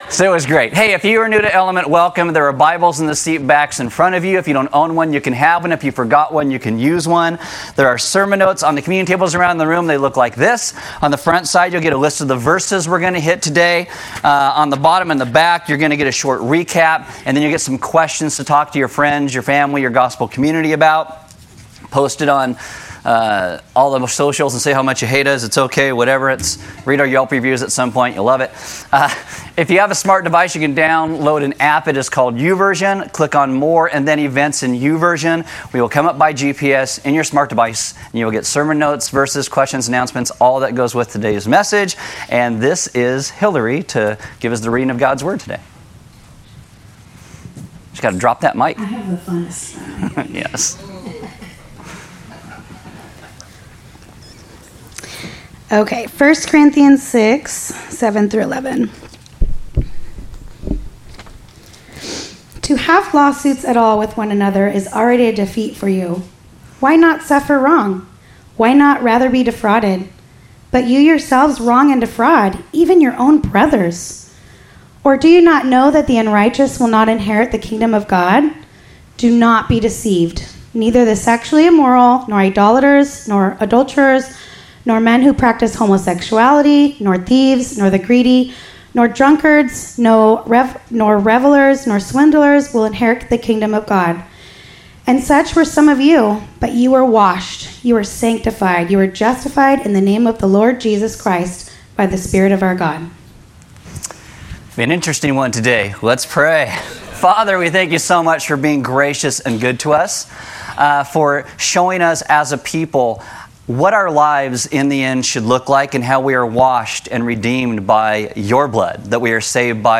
Service Audio Message A message talking about homosexuality is something people need to hear, not hear about (if that makes sense).